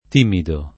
timido [ t & mido ]